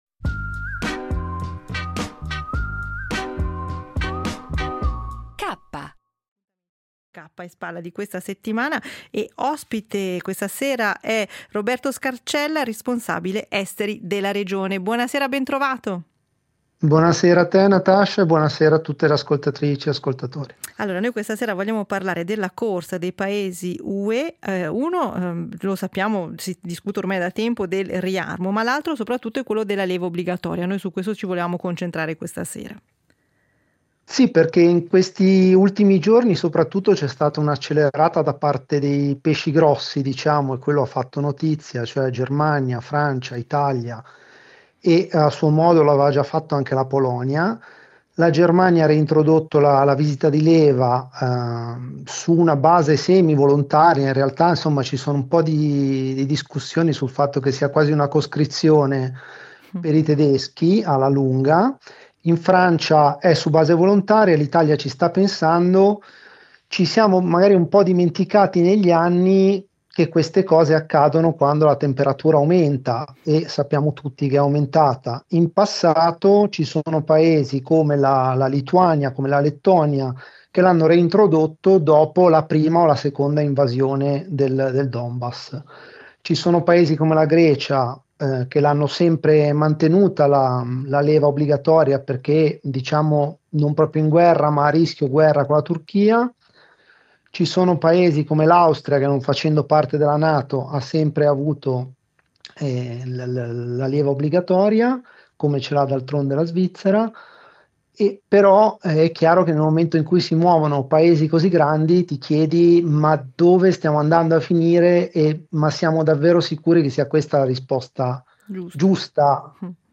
Editoriali tra cultura, attualità e sguardi sul costume